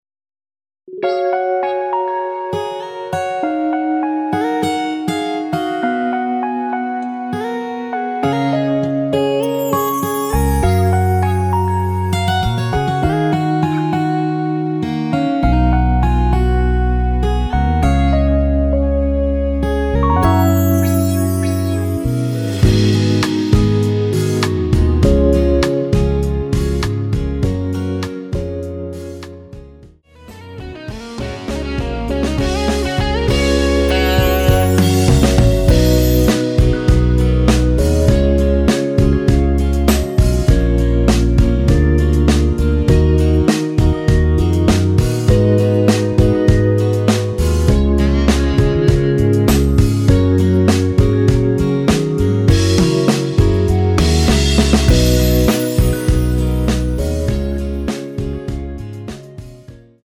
대부분의 남성분이 부르실수 있도록 제작 하였으며 멜로디 포함된 MR 입니다..(미리듣기 참조)
F#
앞부분30초, 뒷부분30초씩 편집해서 올려 드리고 있습니다.
중간에 음이 끈어지고 다시 나오는 이유는